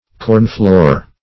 Search Result for " cornfloor" : The Collaborative International Dictionary of English v.0.48: Cornfloor \Corn"floor`\ (k[^o]rn"fl[=o]r`), n. A thrashing floor.